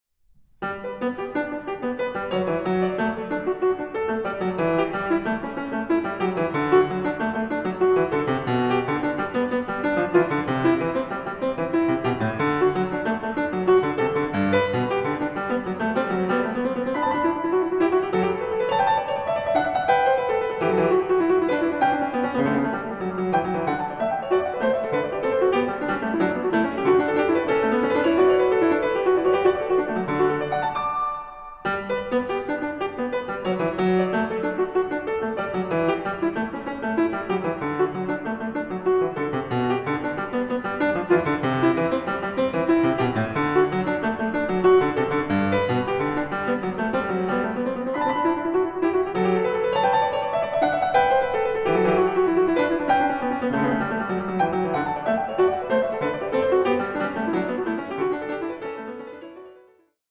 a 2 Clav.